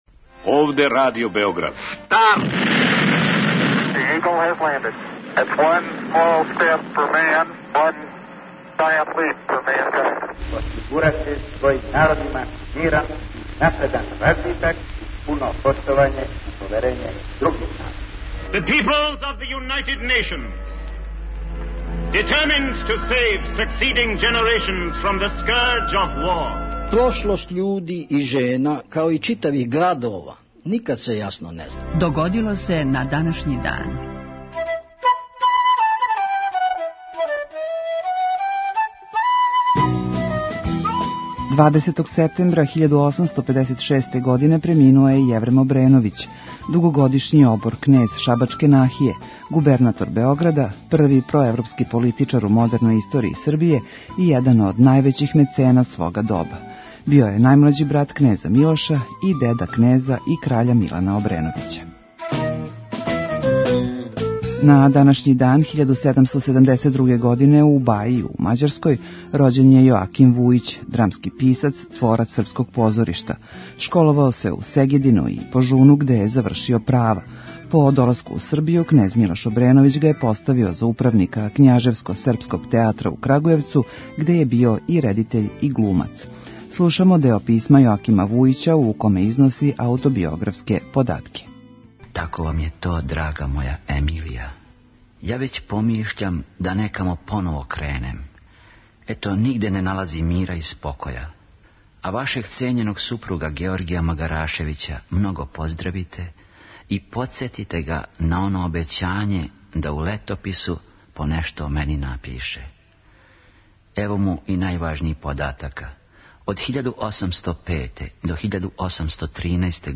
Емисија Догодило се на данашњи дан, једна од најстаријих емисија Радио Београда свакодневни је подсетник на људе и догађаје из наше и светске историје. У петотоминутном прегледу, враћамо се у прошлост и слушамо гласове људи из других епоха.